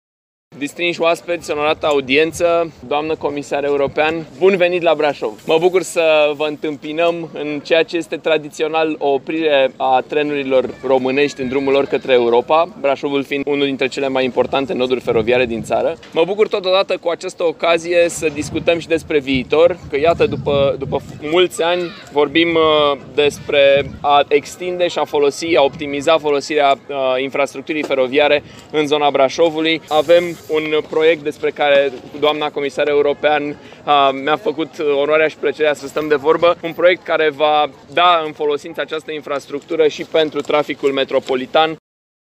Trenul a ajuns în Gara Brașov astăzi, la ora 10.08 și a fost întâmpinat de oficialități locale și județene. Primarul Braşovului, Allen Coliban: